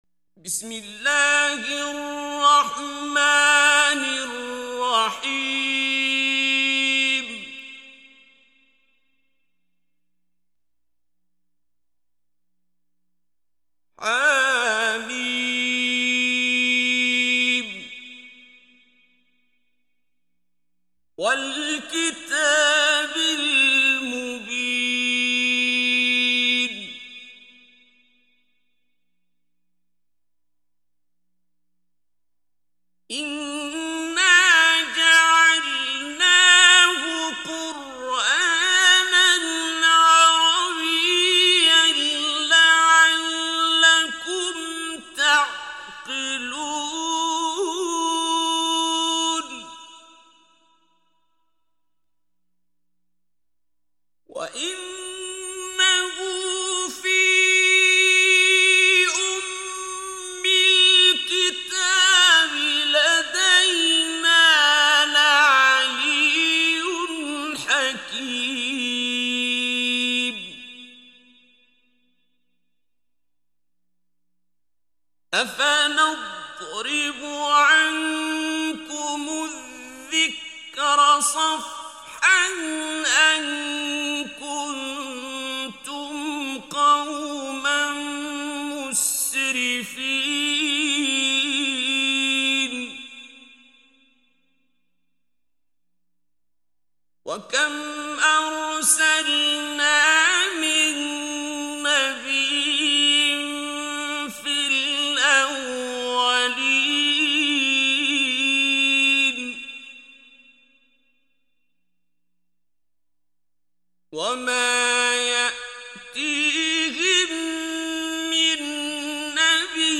ترتیل کل قرآن کریم با صدای استاد شیخ عبدالباسط عبدالصمد به تفکیک سوره های قرآن، با رعایت کامل قواعد تجویدی تقدیم مخاطبان قرآنی